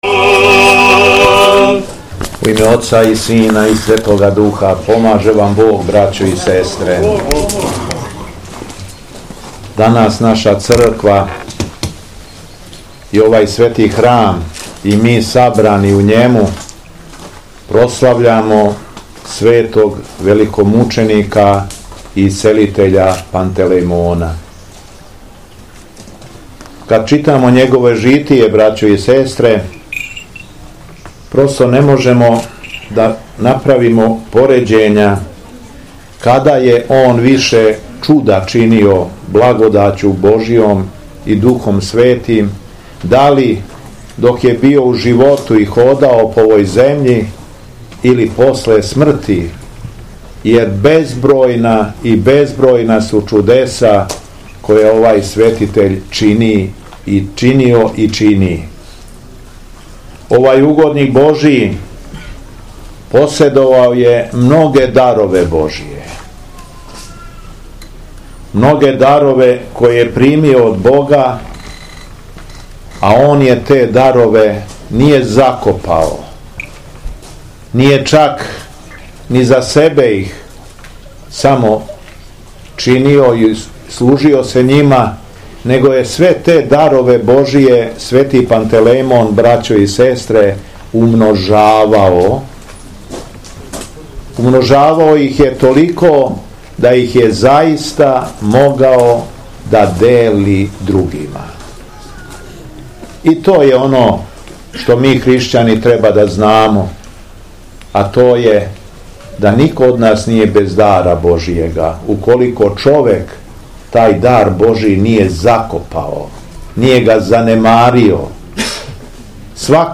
Беседа Његовог Високопреосвештенства Митрополита шумадијског г. Јована
Након читања Светог Јеванђеља, Митрополит Јован је окупљеним верницима честитао храмовну славу и све поучио својом беседом о Светом великомученику Пантелејмону: